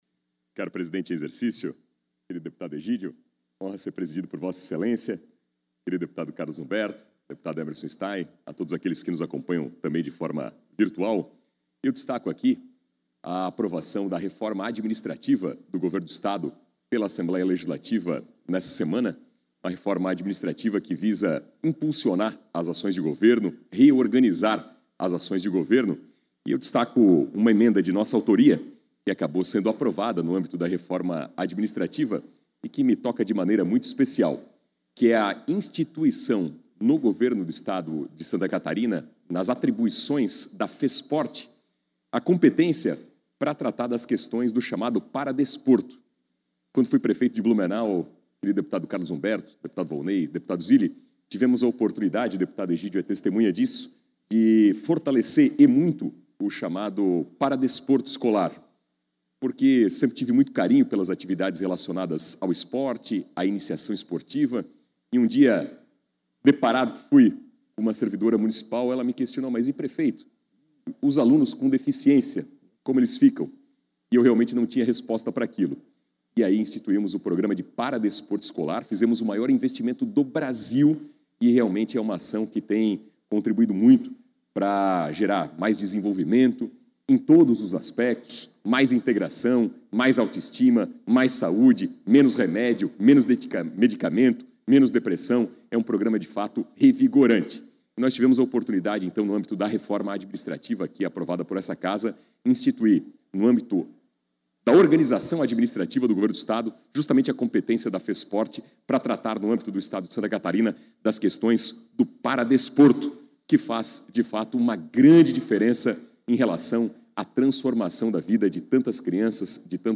Pronunciamento da sessão ordinária desta quinta-feira (11)
Confira o pronunciamento do deputado na sessão ordinária desta quinta-feira (11): - Napoleão Bernardes (PSD).